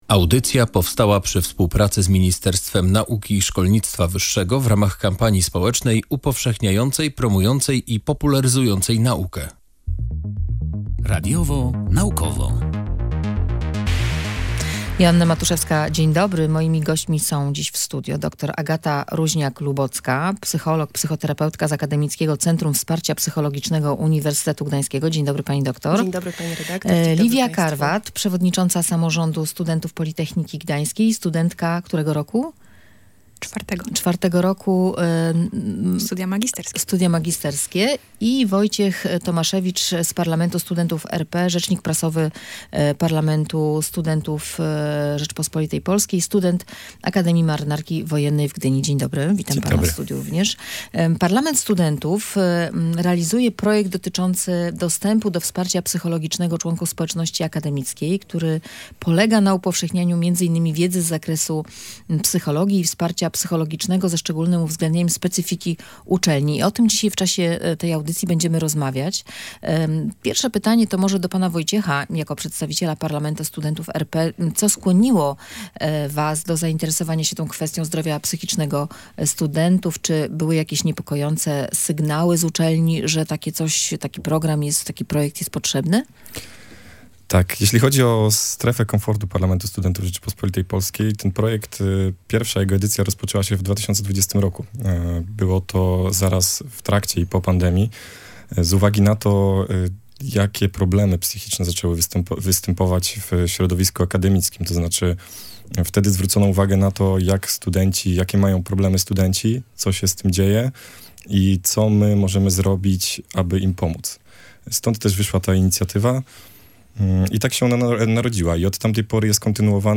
O tym rozmawialiśmy w audycji „Radiowo-Naukowo”.